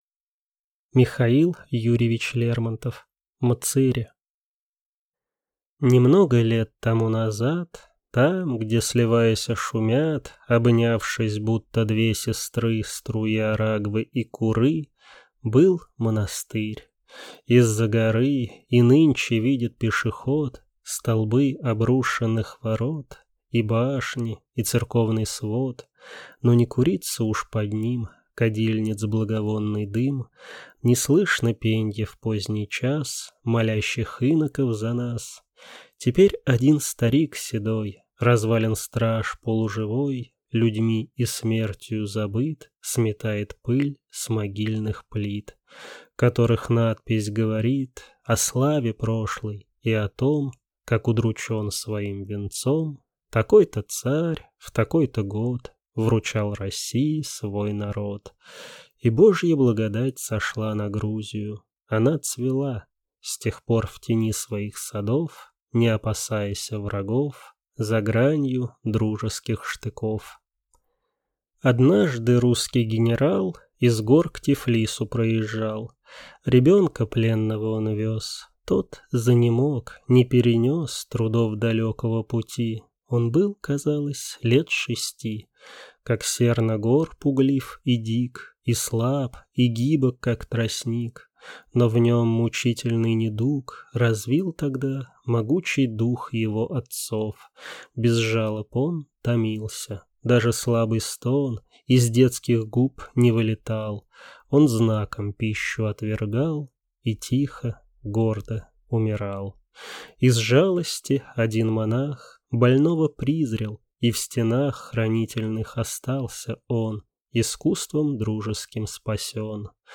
Аудиокнига Мцыри | Библиотека аудиокниг